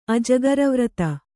♪ ajagarvarata